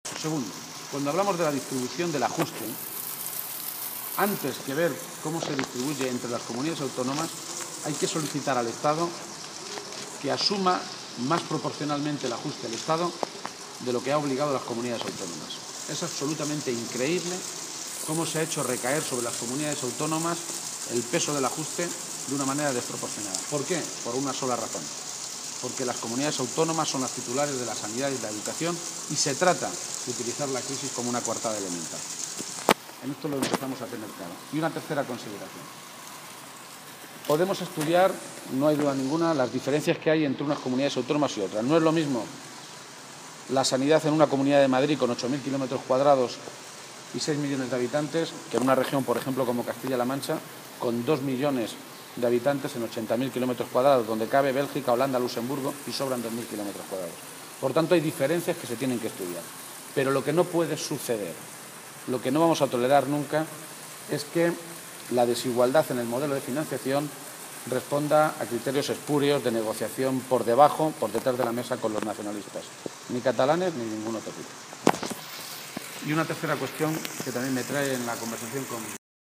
García-Page se ha pronunciado así en declaraciones a los medios de comunicación, en las Cortes de Aragón, antes de reunirse con el secretario general del PSOE aragonés, Javier Lambán, donde ha manifestado que el debate sobre la financiación autonómica «se empieza a enmarañar».
Cortes de audio de la rueda de prensa